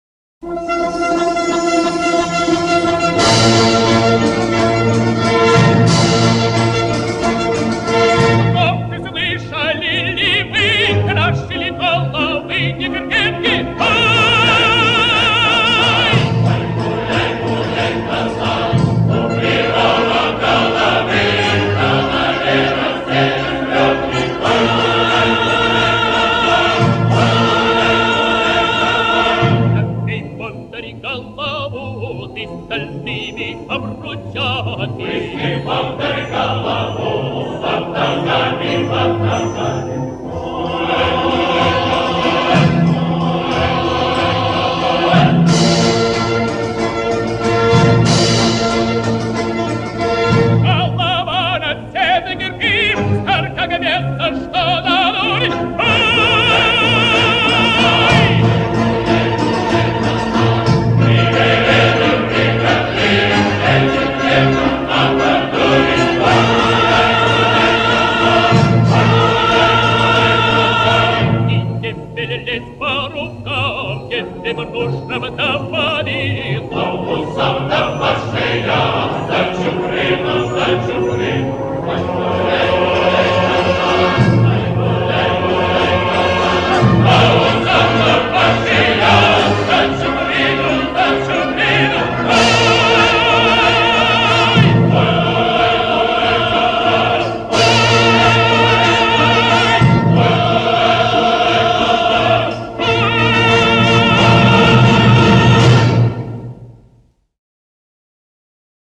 Песня из оперы